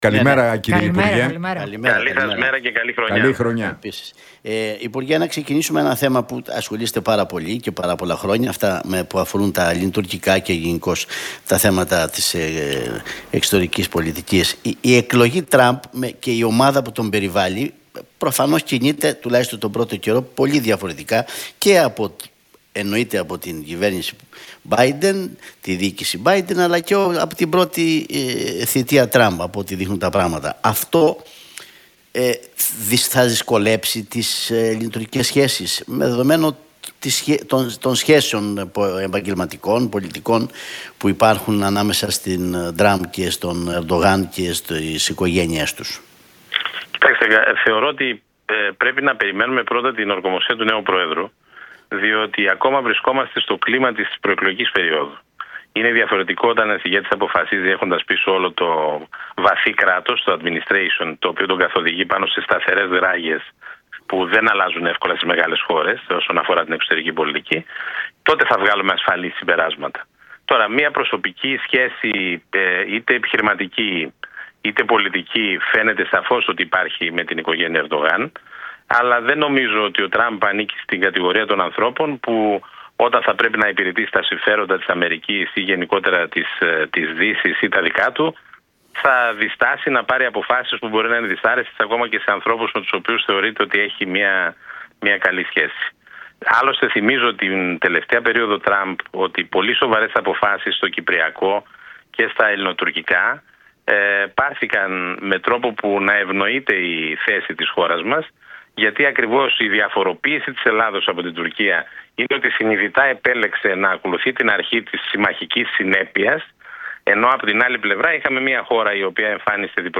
Στυλιανίδης στον Realfm 97,8: Ο Πρόεδρος της Δημοκρατίας μπορεί να προέρχεται και από δεξιά και από αριστερά